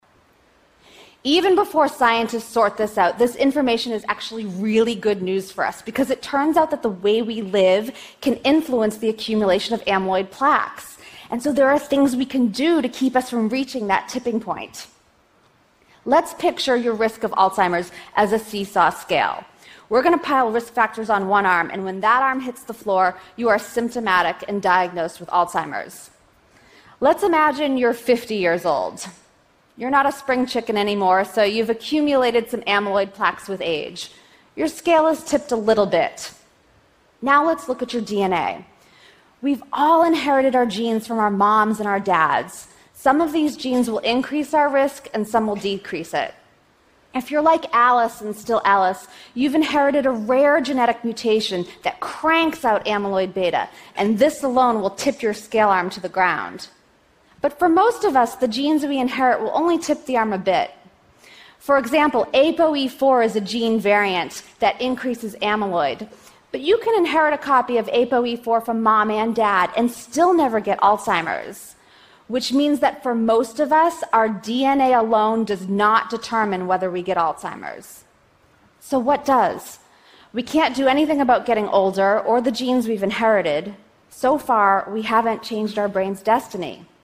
TED演讲:和老年痴呆症说再见(5) 听力文件下载—在线英语听力室